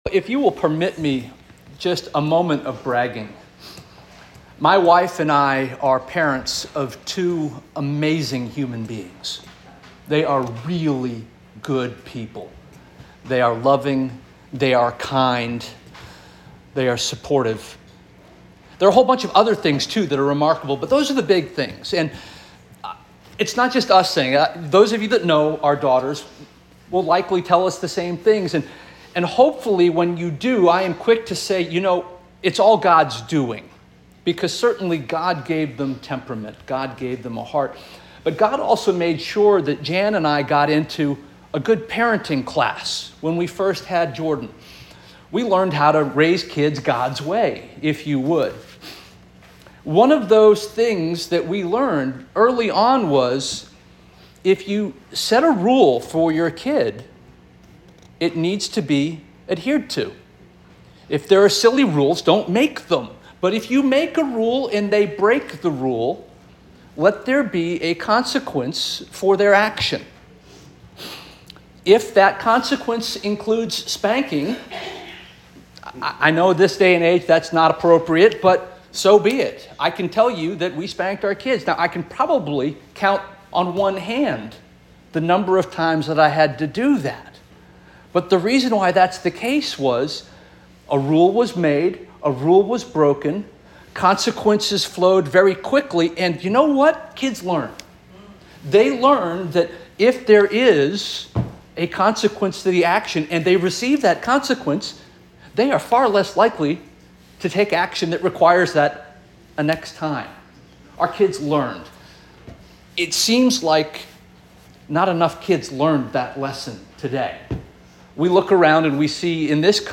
May 5 2024 Sermon